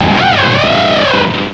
cry_not_luxray.aif